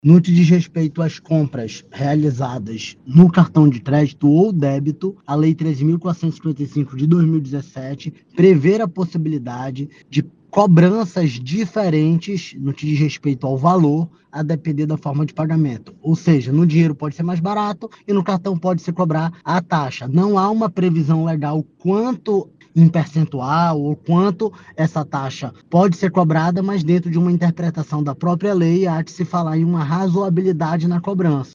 SONORA-2-JALIL-FRAXE.mp3